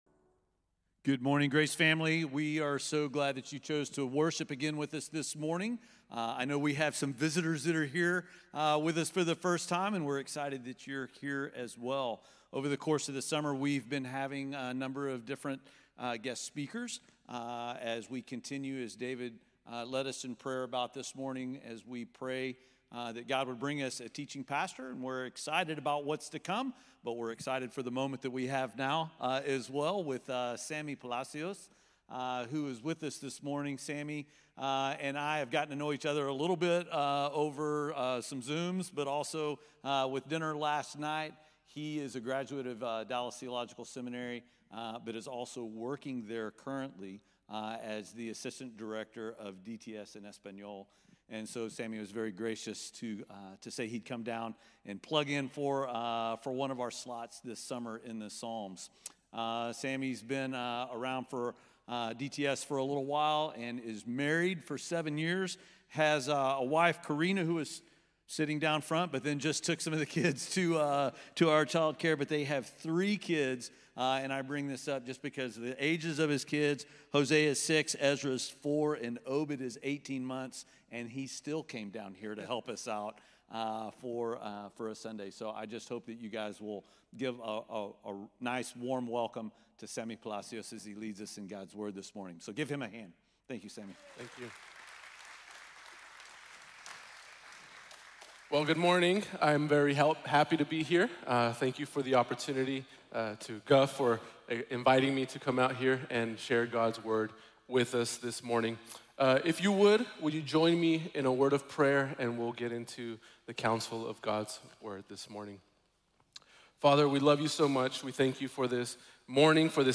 Acknowledging the Provider | Sermon | Grace Bible Church